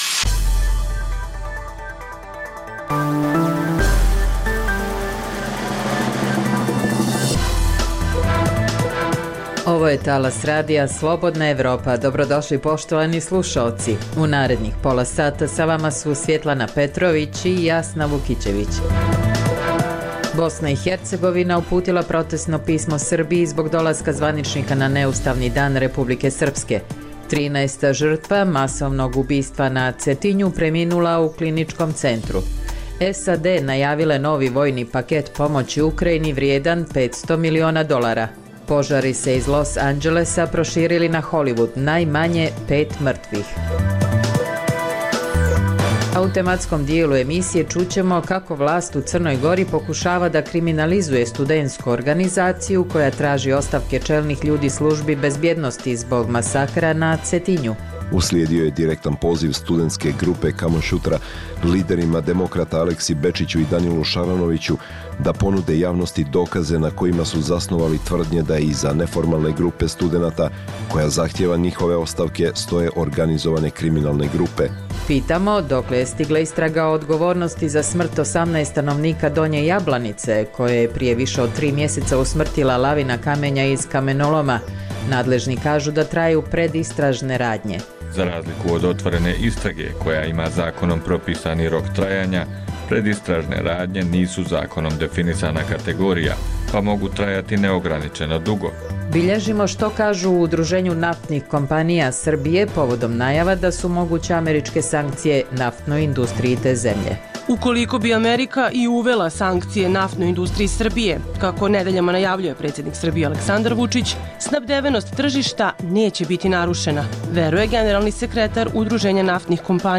Dnevna informativna regionalna emisija Radija Slobodna Evropa o dešavanjima u zemljama Zapadnog Balkana i svijeta.